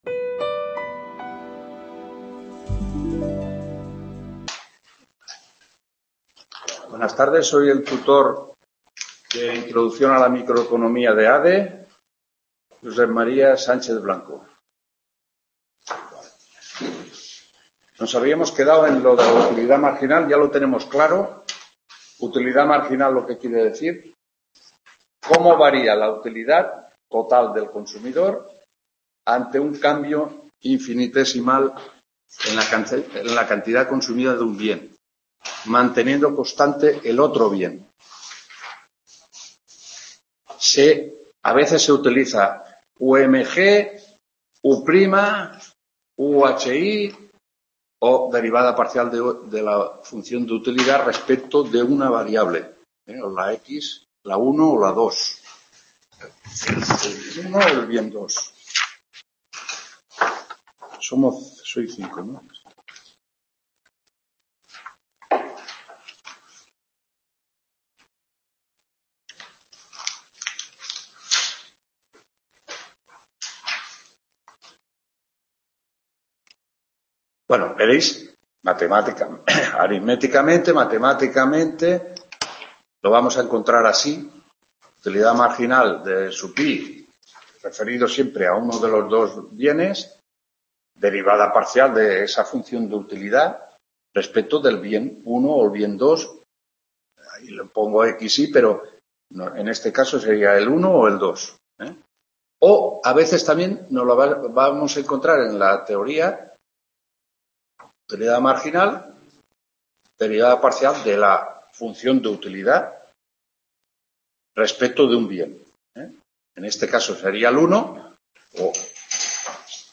4ª TUTORÍA INTRODUCCIÓN A LA MICROECONOMÍA (ADE) 19-11…